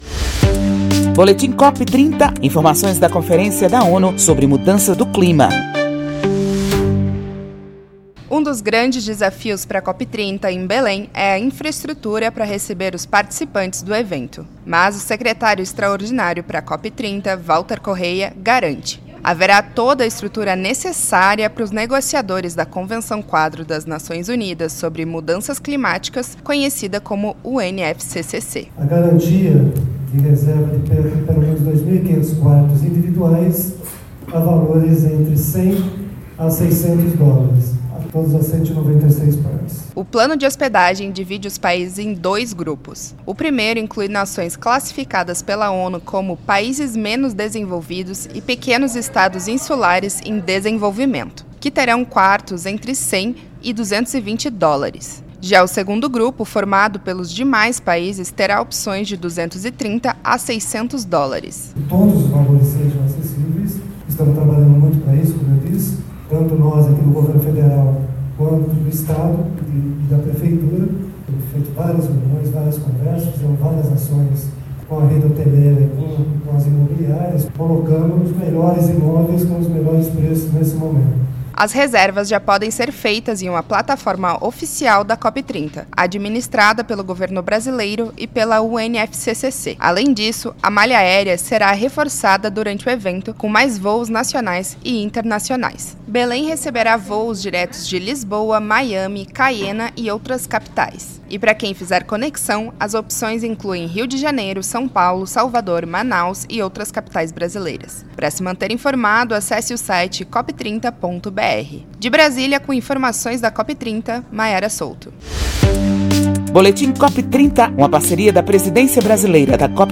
BOLETIM DE RÁDIO COP30 BRASIL